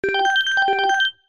フリー音源効果音「警告音」「緊急音」です。
フリー音源 「警告音」「緊急音」1
緊急音1